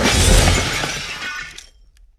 crash1.ogg